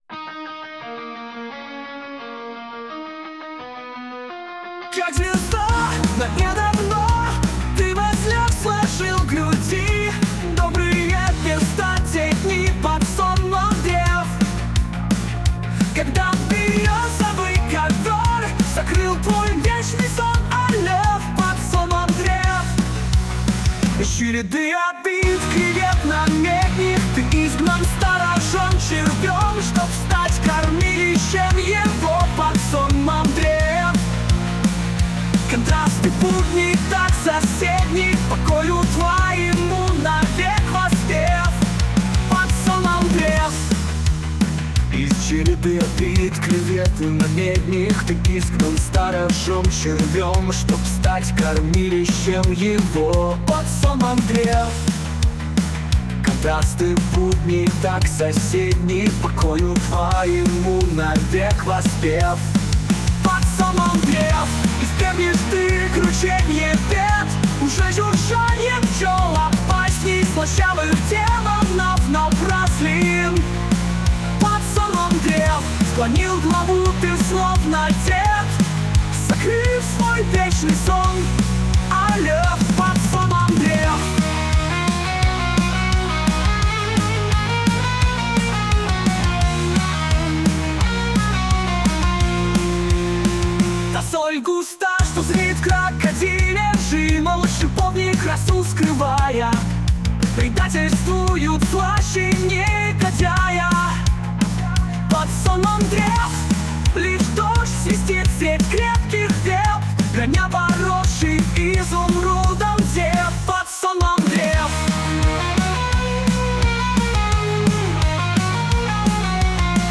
mono-продолжение нейрогена рок поэзии.